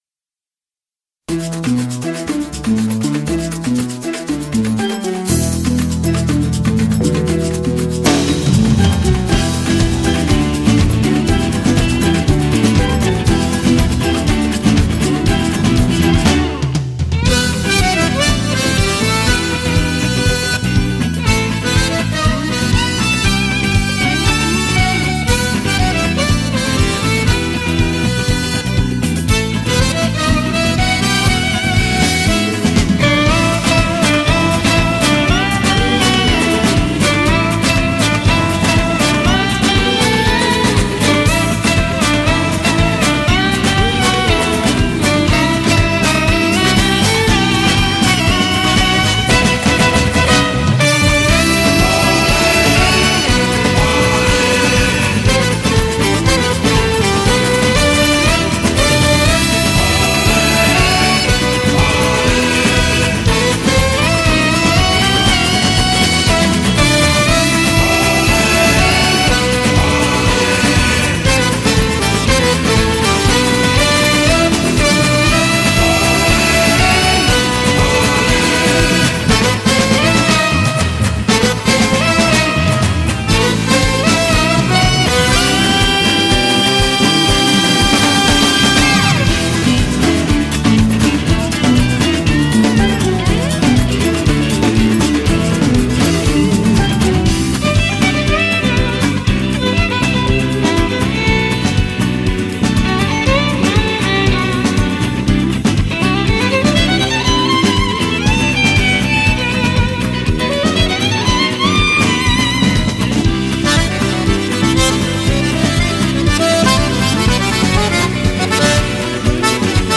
サンバのリズムにのって篳篥、バイオリン、アコーディオンが軽やかにスウィングする。